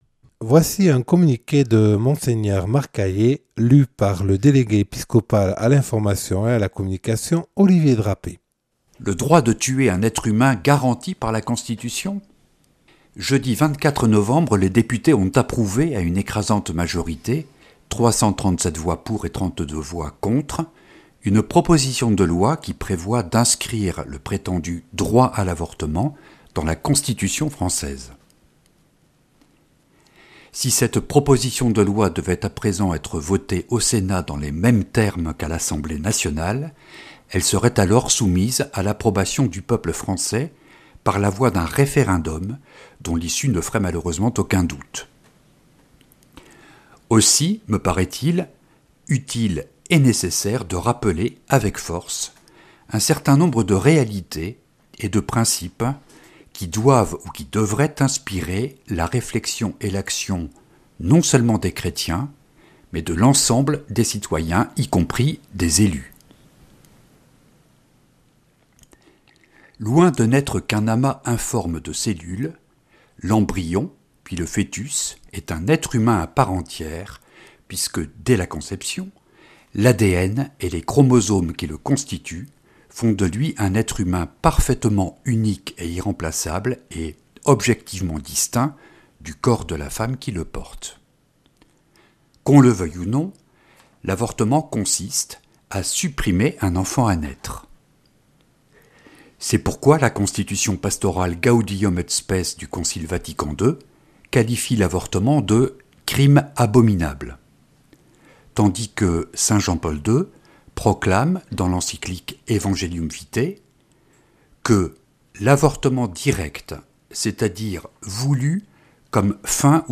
Communiqué de Mgr Aillet du 25 novembre 2022.